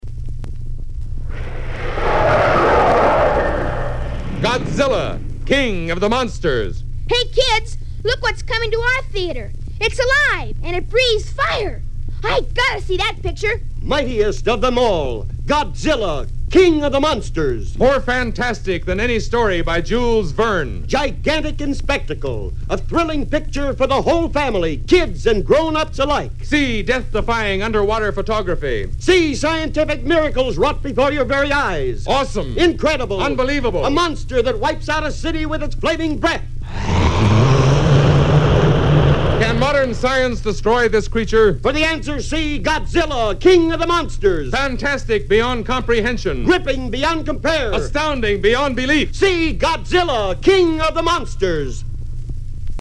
Oddly enough, Godzilla’s characteristic roar is not heard in these spots.
So, here they are…as listeners would have heard them back in 1956!
Godzilla King of the Monsters Radio Spots for 12, 50, and 100 seconds versions.